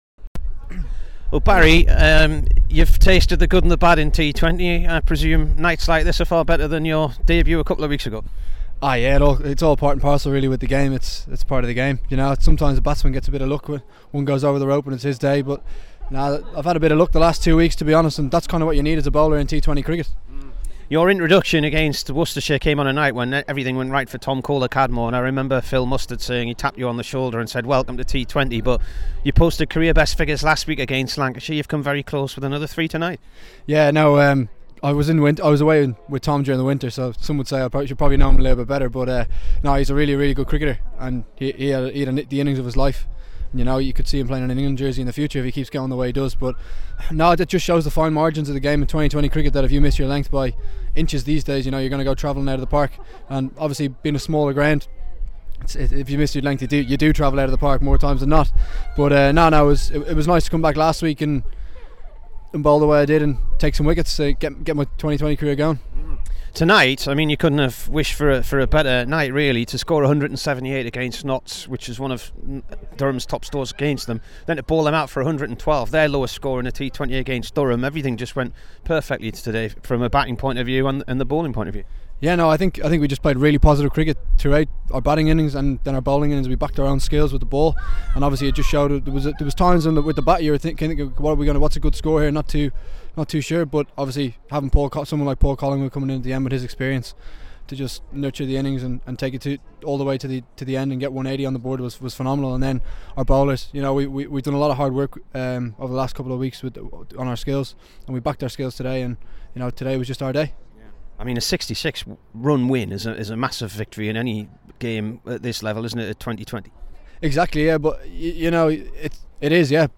Barry McCarthy int